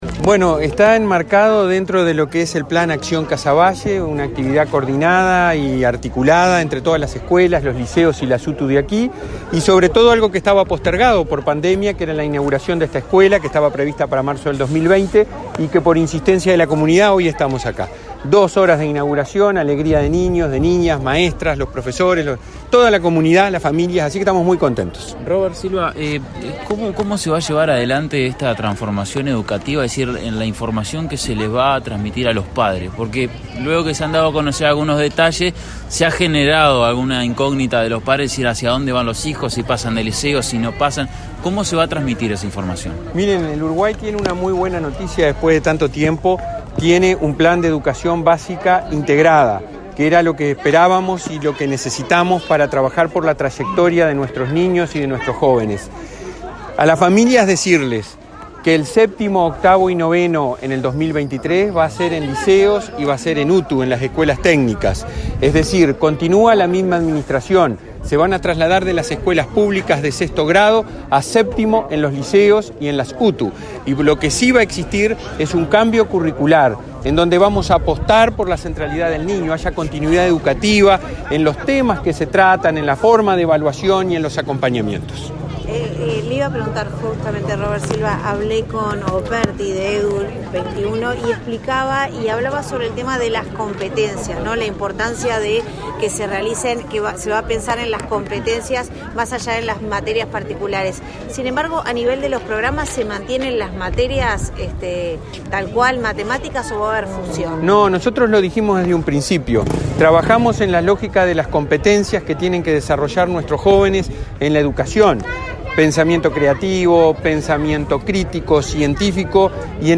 Declaraciones del presidente de la ANEP, Robert Silva
Declaraciones del presidente de la ANEP, Robert Silva 28/09/2022 Compartir Facebook X Copiar enlace WhatsApp LinkedIn El presidente de la ANEP, Robert Silva, participó en la inauguración de la sede de la escuela n.° 319, en el barrio Casavalle, de Montevideo, y luego dialogó con la prensa.